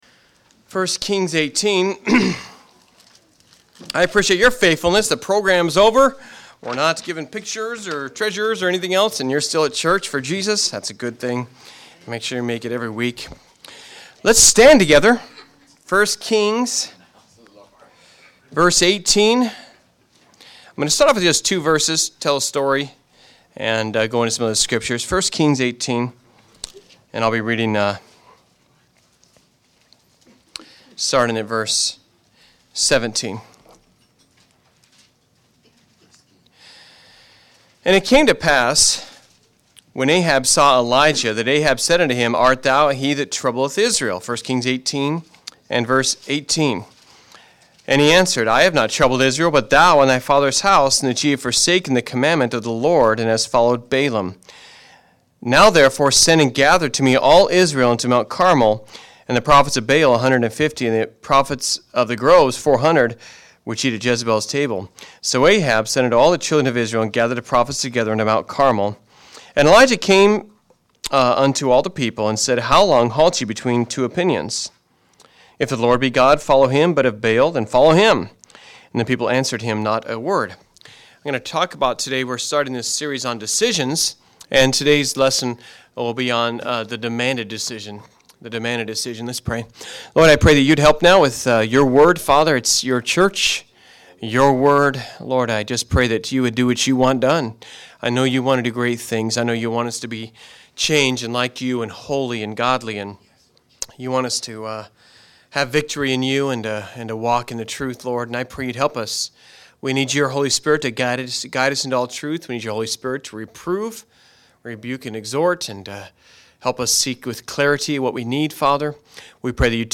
ODBC Audio Sermons